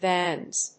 /vænz(米国英語)/